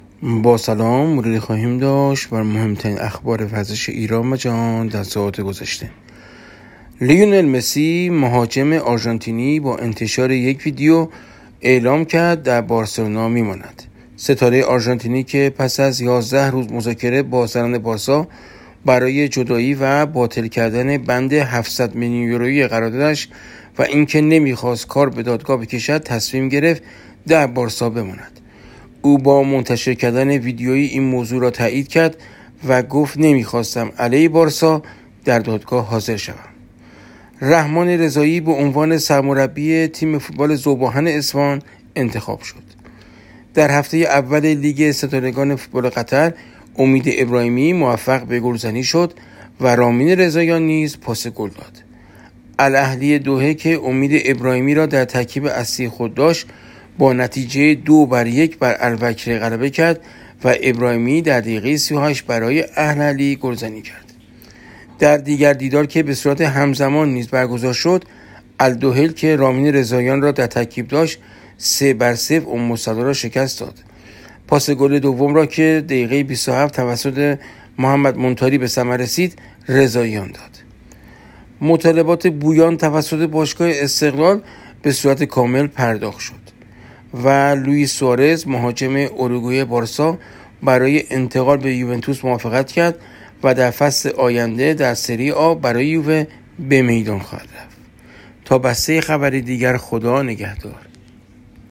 بسته صوتی ۱۵ شهریور از اتفاقات ورزش ایران و جهان 26/آذر/1404 | 07:57 | صفحه نخست جلد و PDF مجله جلد برگزیده های مجله کیهان ورزشی مولتی مدیا مالتی مدیا ورزشی دانلود نسخه pdf مجله تله تایپ: " زنگ خطر" برای فوتبال!